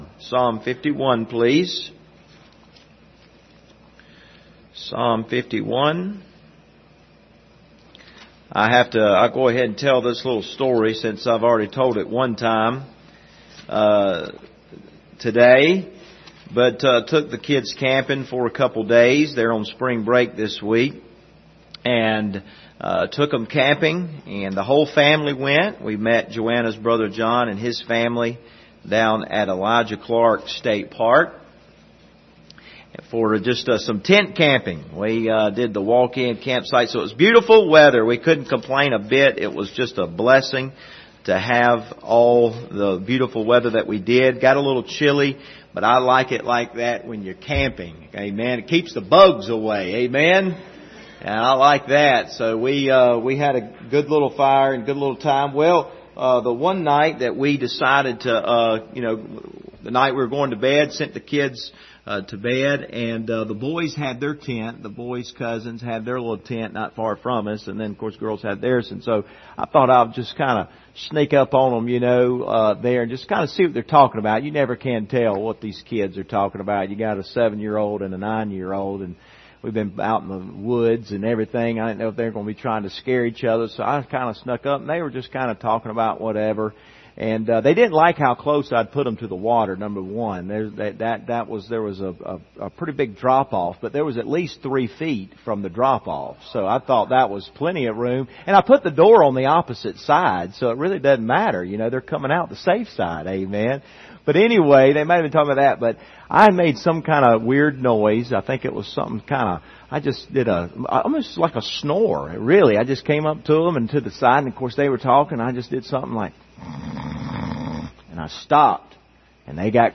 Service Type: Wednesday Evening Topics: confession , repentance